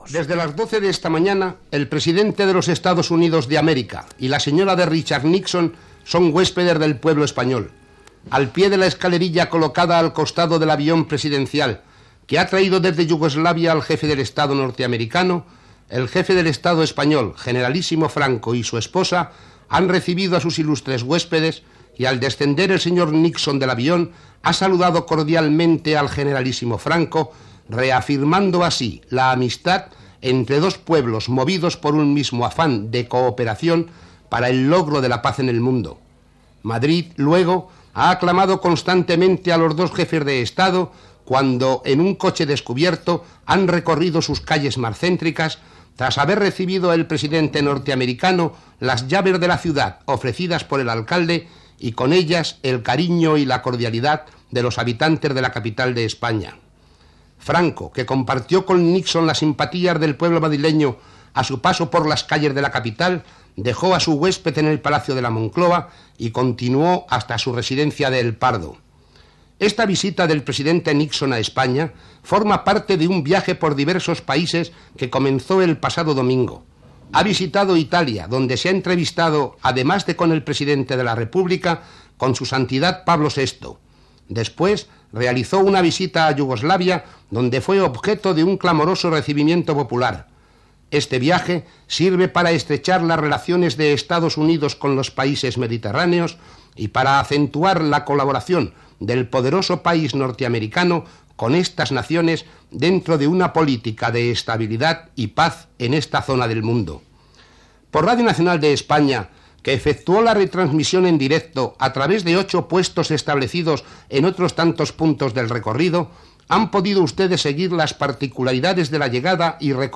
Informació de la visita del president dels EE.UU. Richard Nixon a Espanya, arribada a Madrid i països que havia visitat Nixon abans d'arribar a Espanya. Resum informatiu de la jornada amb les paraules del "generalísimo" Franco
Informatiu